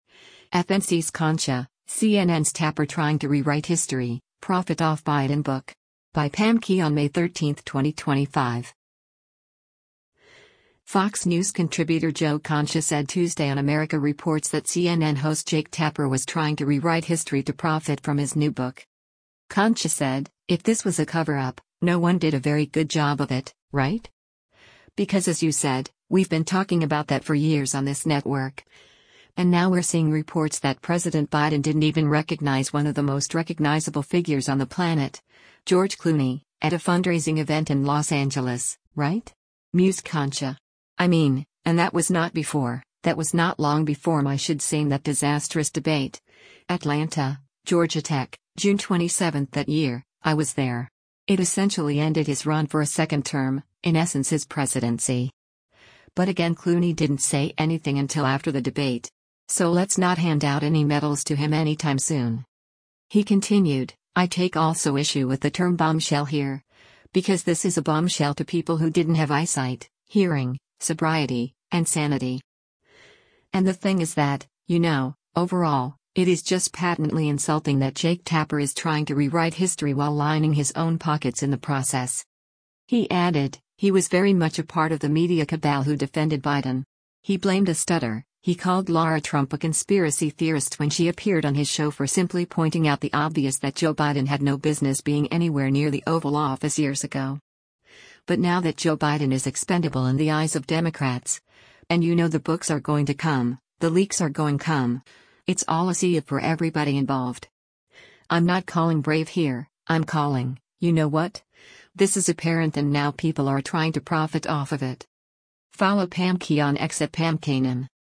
Fox News contributor Joe Concha said Tuesday on “America Reports” that CNN host Jake Tapper was “trying to rewrite history” to “profit” from his new book.